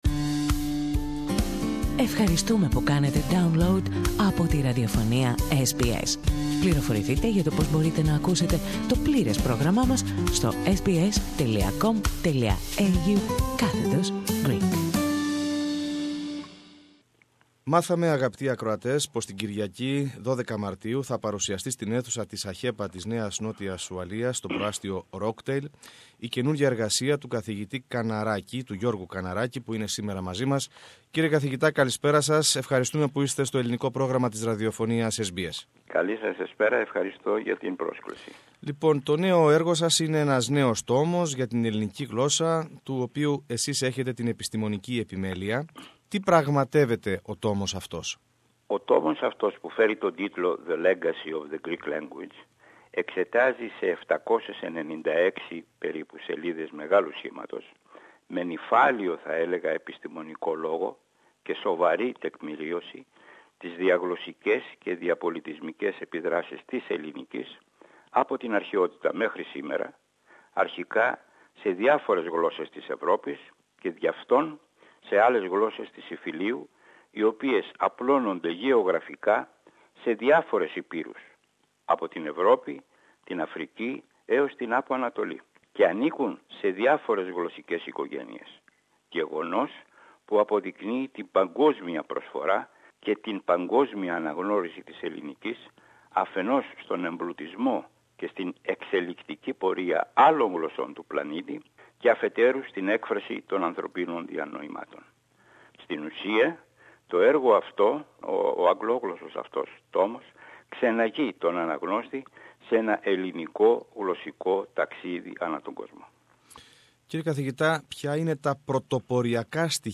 Η απομαγνητοφωνημένη συνέντευξη έχει ως ακολούθως: